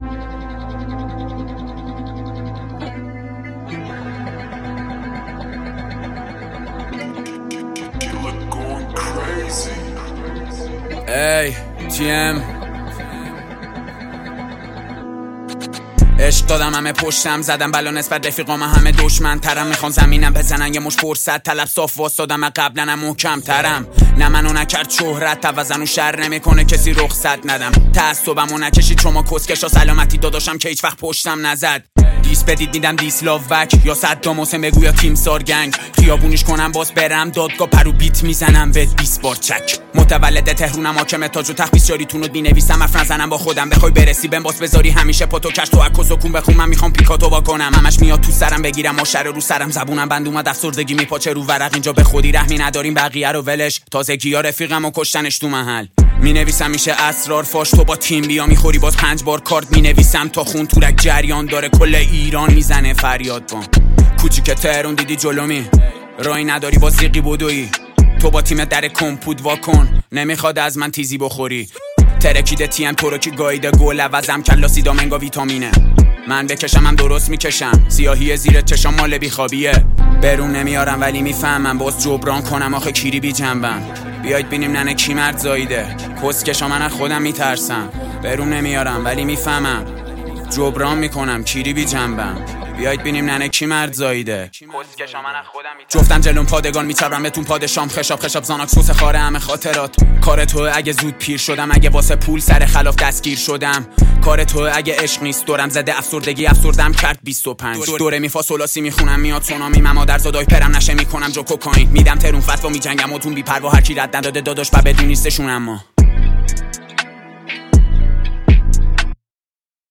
• رپ